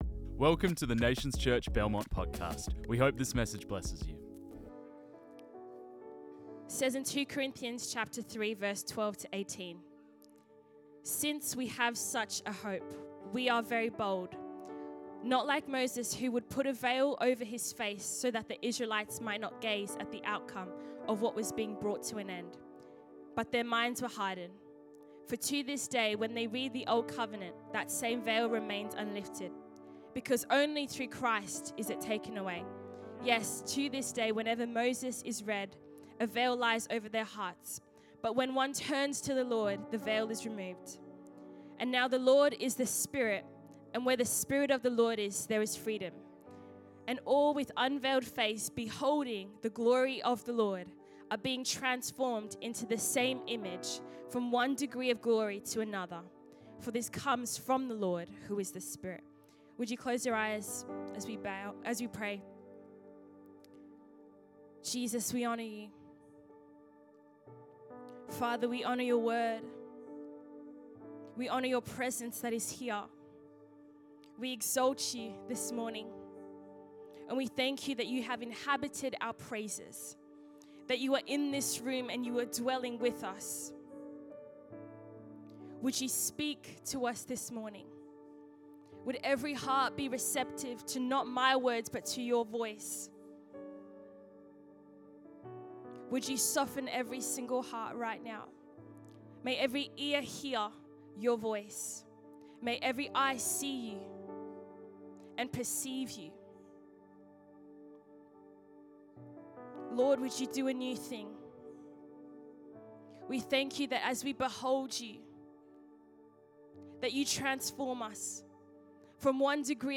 This message was preached on 04 May 2025.